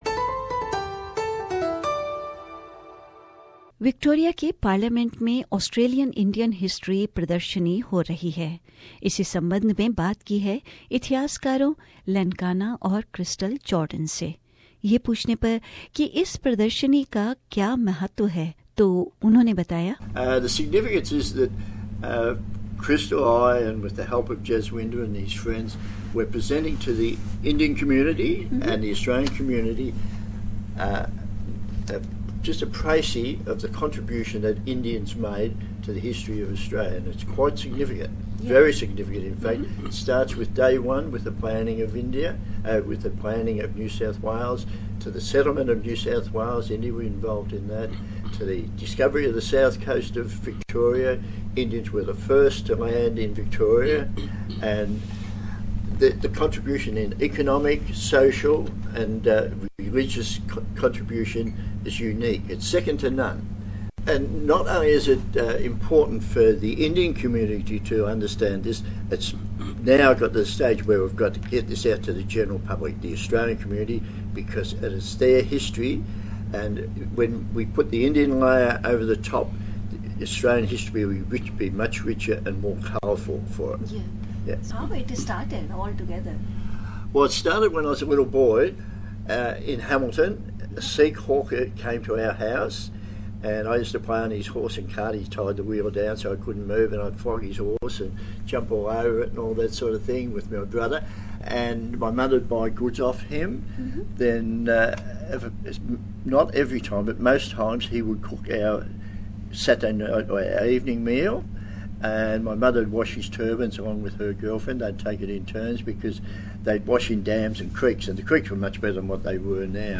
बातचीत में इसके बारे में बता रहे हैं।